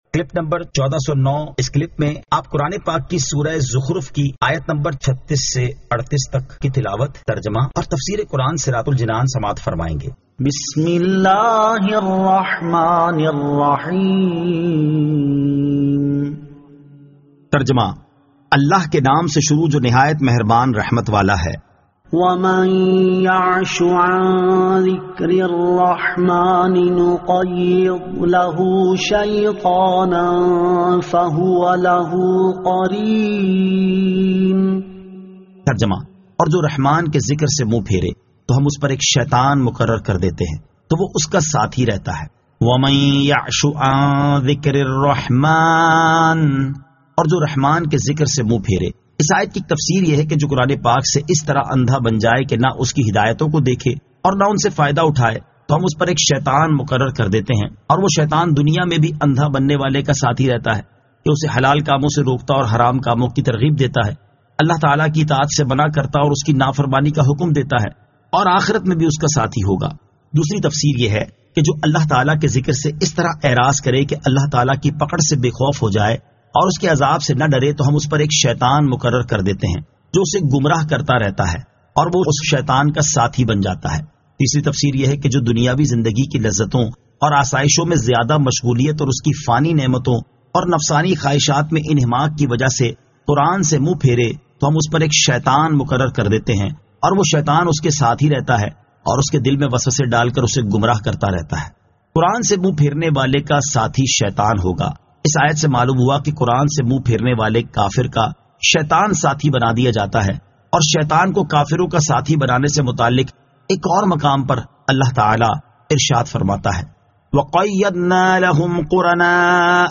Surah Az-Zukhruf 36 To 38 Tilawat , Tarjama , Tafseer